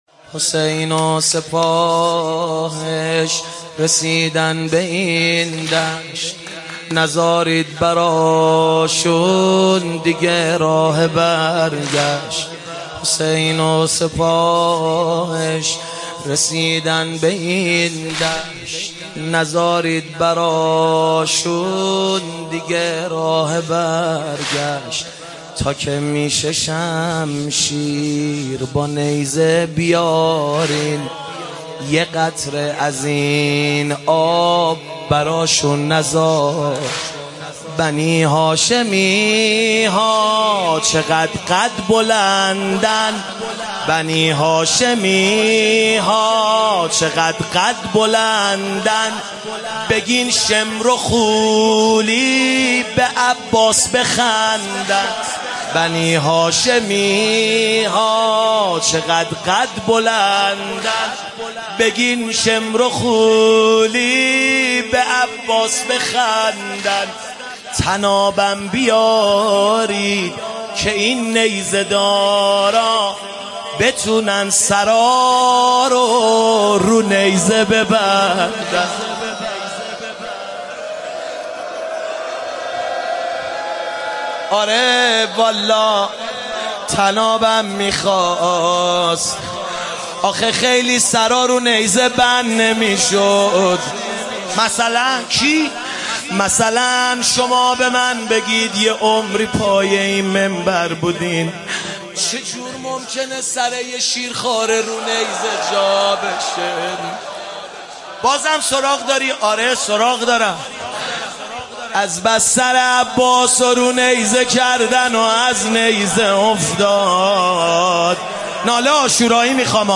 نوحه جديد
حسین و سپاهش رسیدند _ واحد
شب دوم محرم